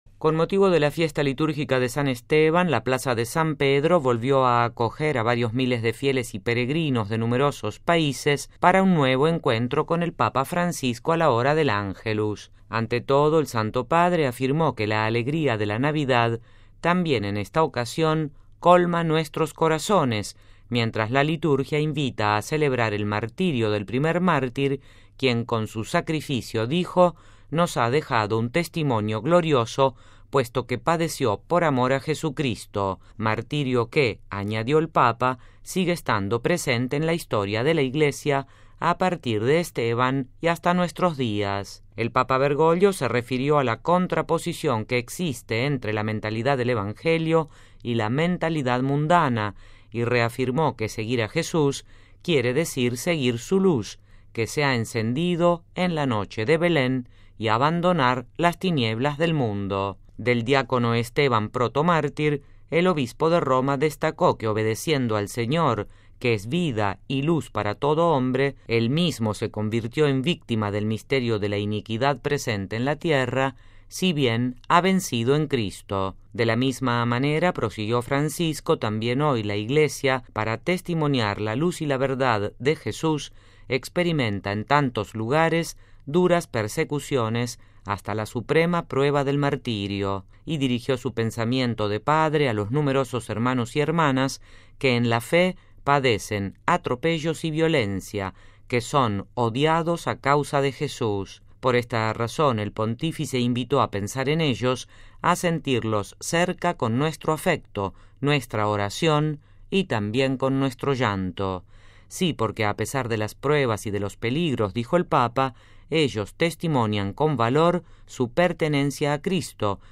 (RV).- Con motivo de la fiesta litúrgica de San Esteban, la Plaza de San Pedro volvió a acoger a varios miles de fieles y peregrinos de numerosos países para un nuevo encuentro con el Papa Francisco a la hora del Ángelus.